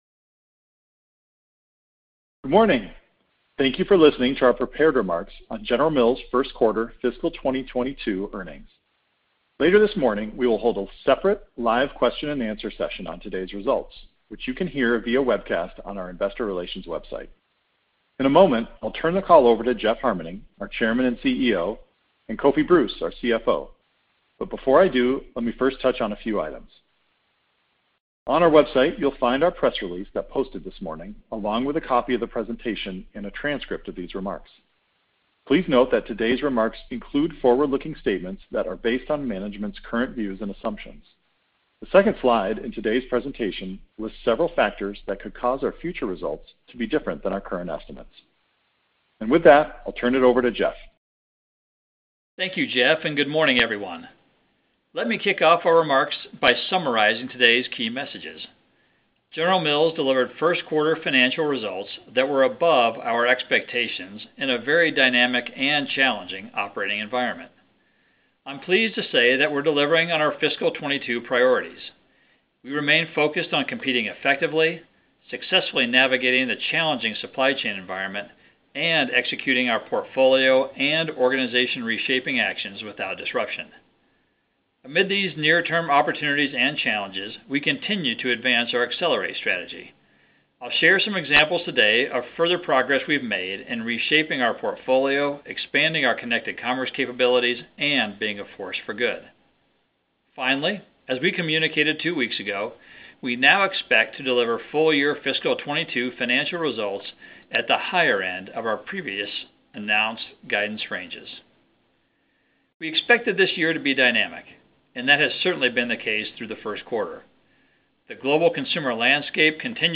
Recording - Prepared Remarks (opens in new window)
General_Mills_F22Q1_Pre-record_V1.mp3